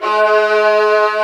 STR VIOLA01R.wav